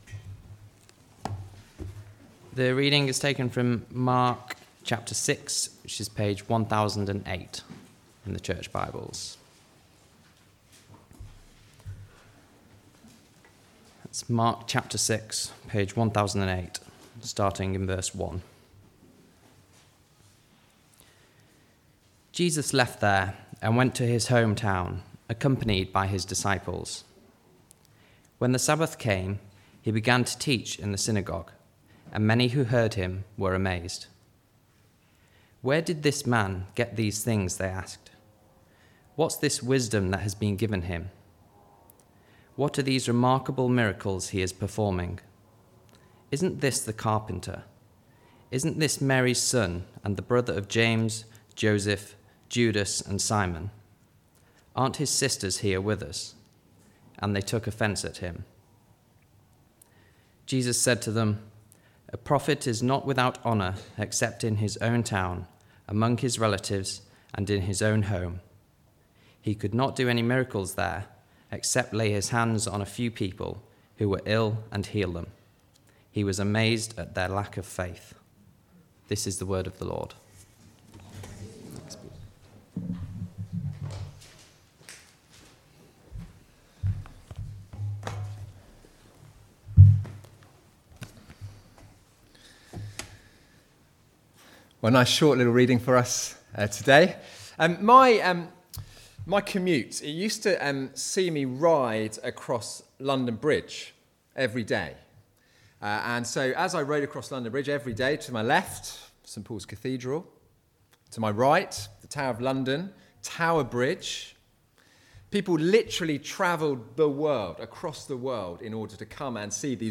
Follow the King Passage: Mark 6:1-6 Service Type: Weekly Service at 4pm « “Don’t be afraid